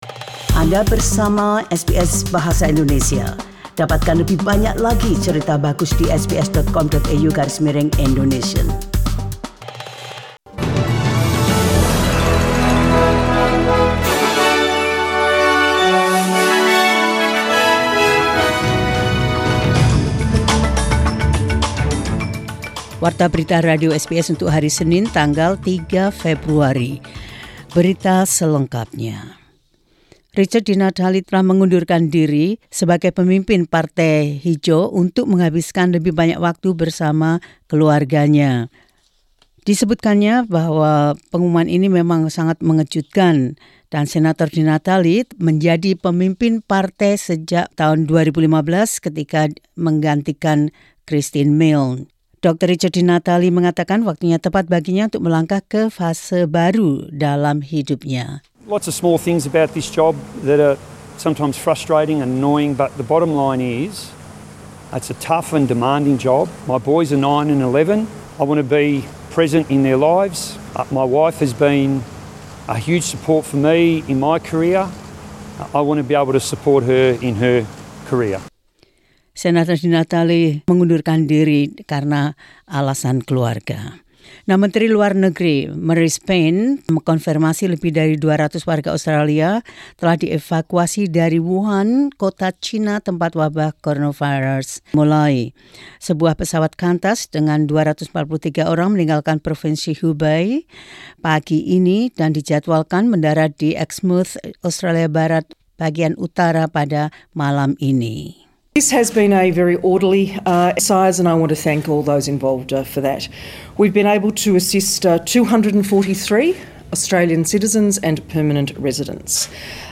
SBS Radio News in Indonesian 3 Feb 2020.